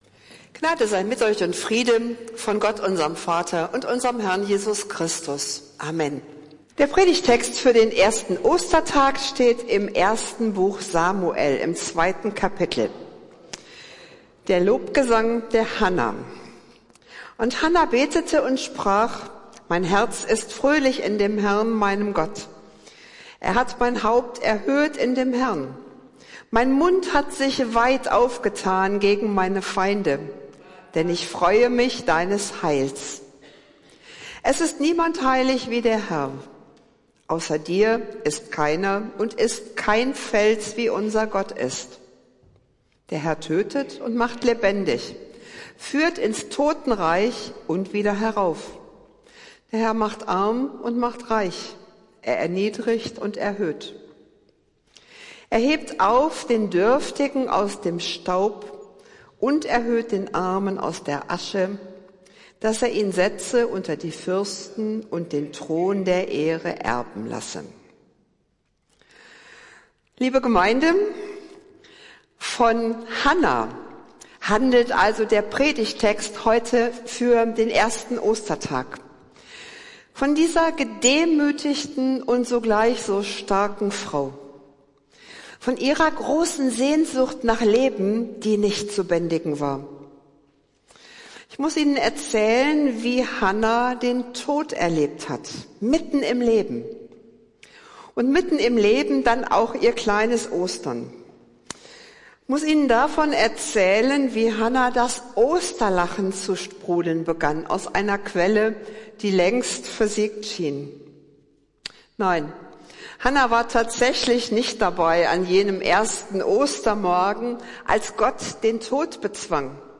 Predigt des Gottesdienstes aus der Zionskirche vom Ostersonntag, den 31. März 2024